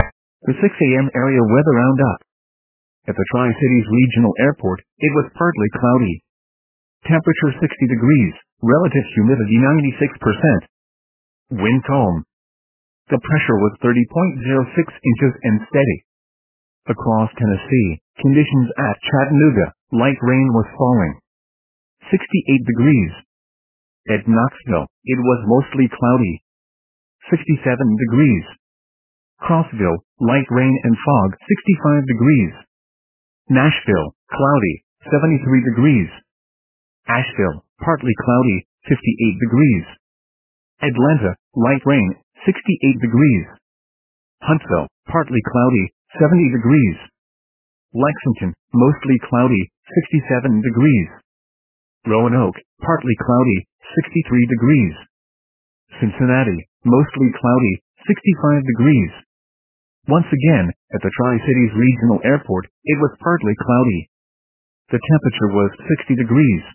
MRX Weather Radio Forecasts